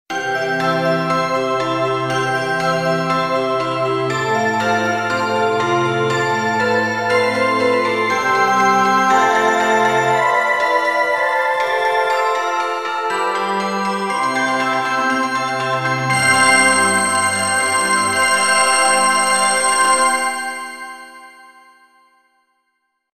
発車メロディー一覧
1番線 普通･急行ホーム 桜乃･凛咲･直通(中山･鱒ノ宮･関門台･神京･姫川中央)方面
12 発車メロディー.mp3